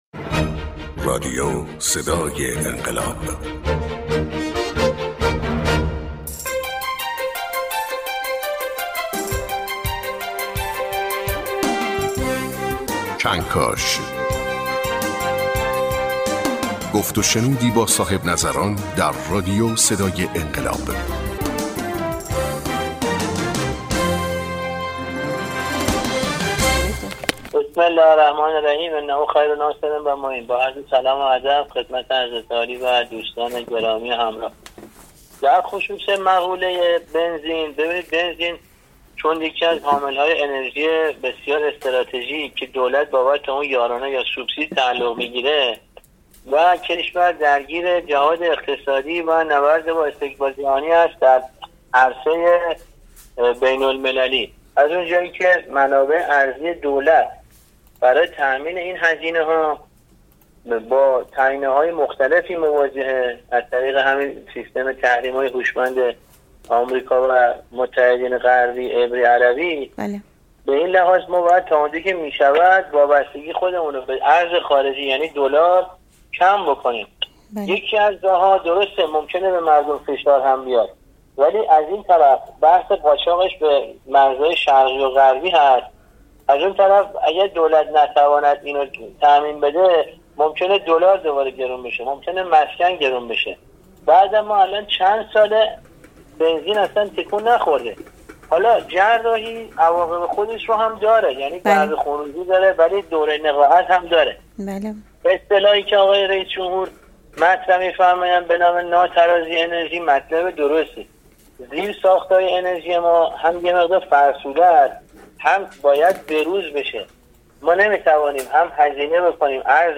رادیو صدای انقلاب 1488 | کنکاش: گفت‌ وشنودی با صاحب‌نظران در رادیو صدای انقلاب